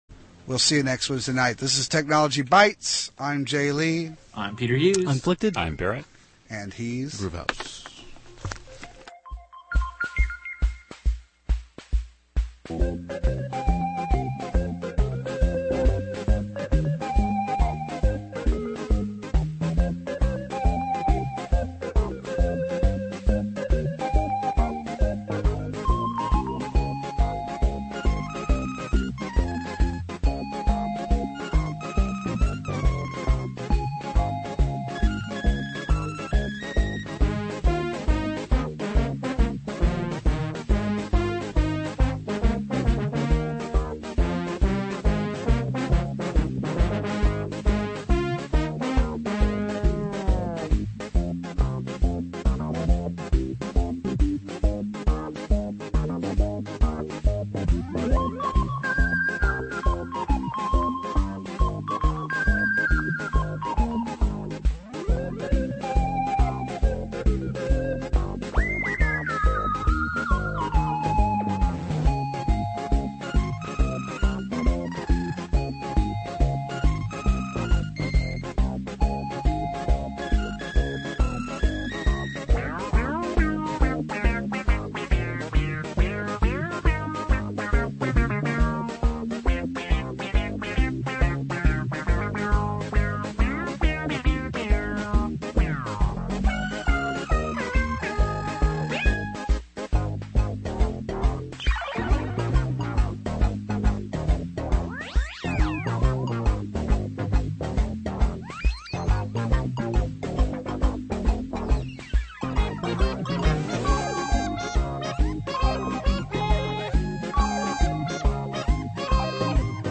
a listener call-in show (every Wednesday night from 10-11PM CT) on KPFT-FM 90.1 (Houston).